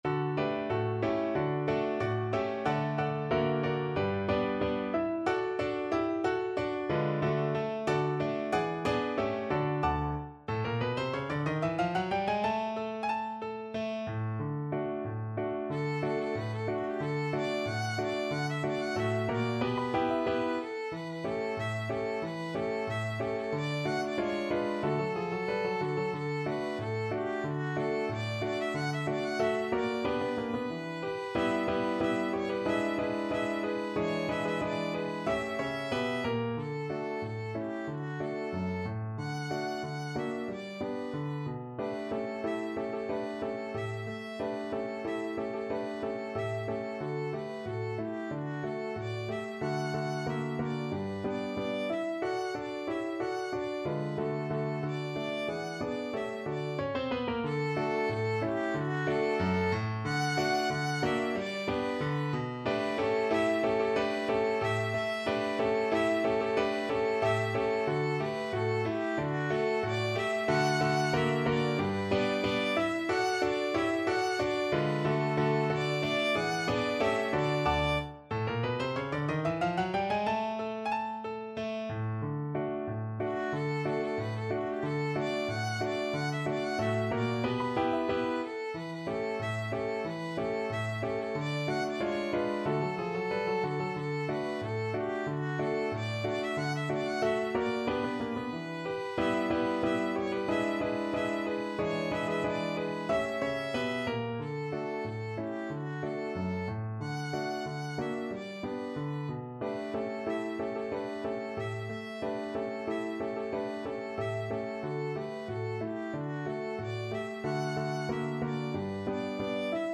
Violin version
Allegretto =92
2/4 (View more 2/4 Music)
Traditional (View more Traditional Violin Music)